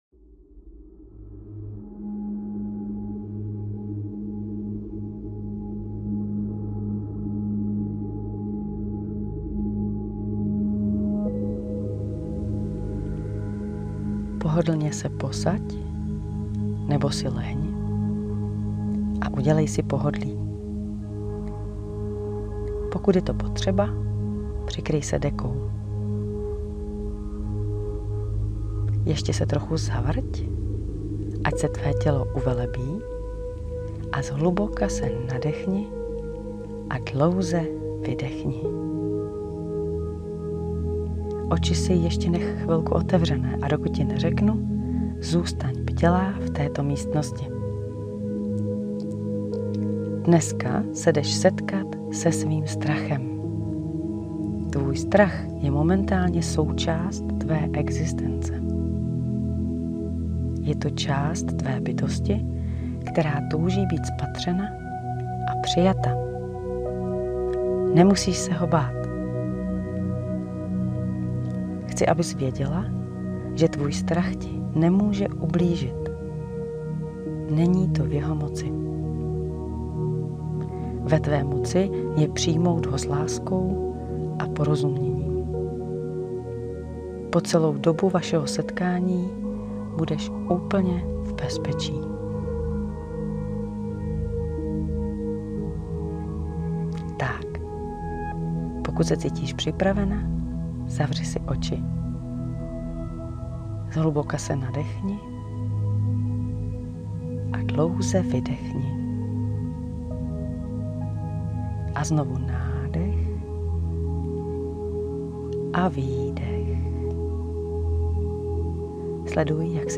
Praxe 2. dne výzvy - meditace setkání se strachem
Meditace-setkání-se-svým-strachem.mp3